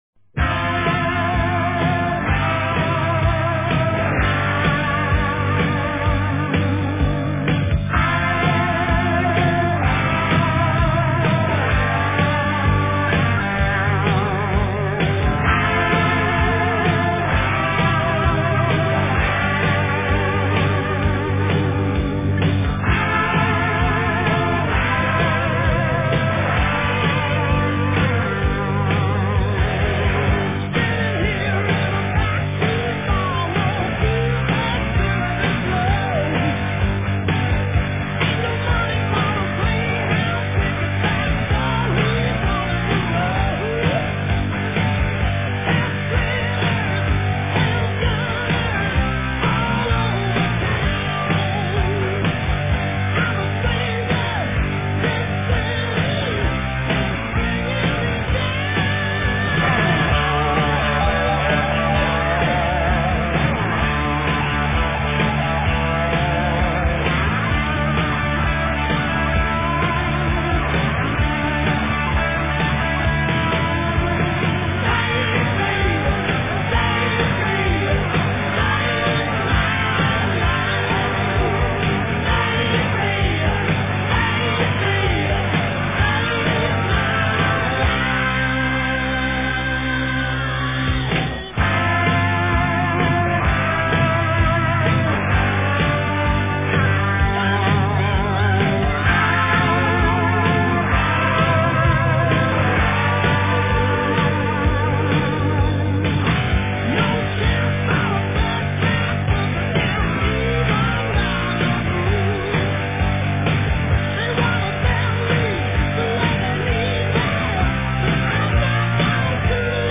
Жанры: Хэви-метал, Хард-рок